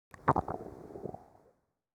Un petit gargouilli de ventre.
Écouter : Gargouillement de ventre #5 (1 s)